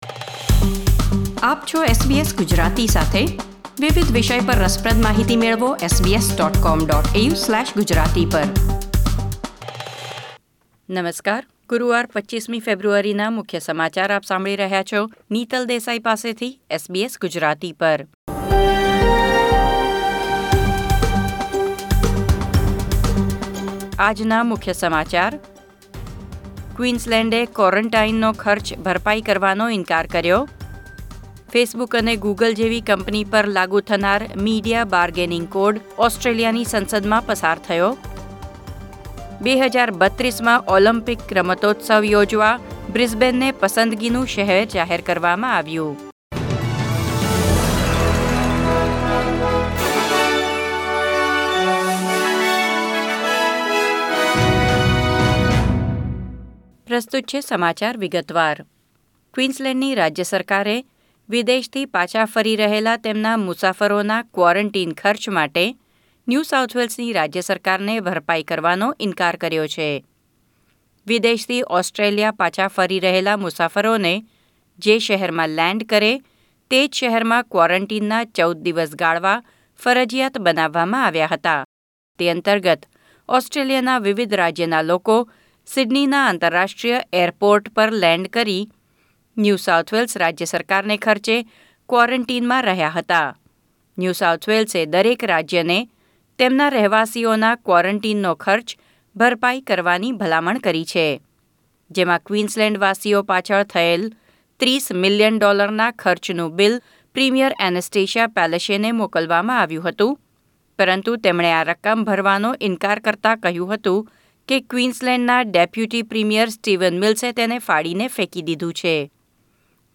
SBS Gujarati News Bulletin 25 February 2021